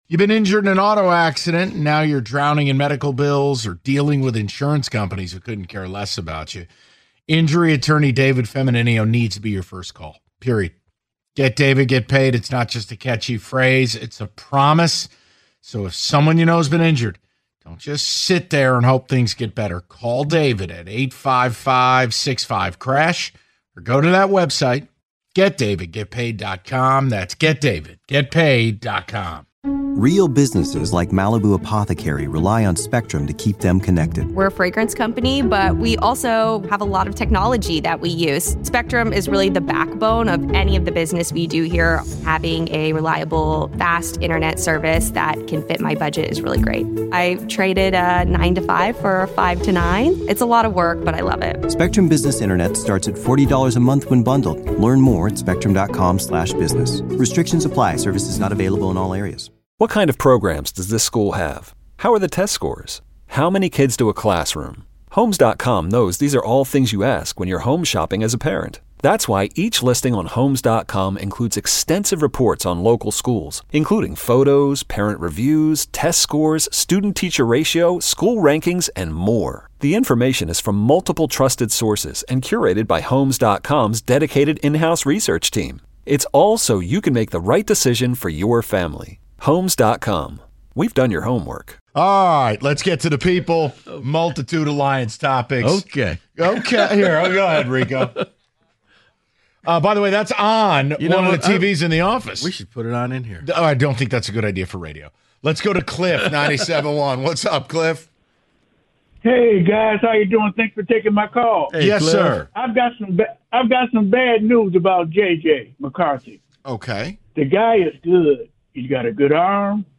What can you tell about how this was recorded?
The Callers Chime In On Expensive Lions Tickets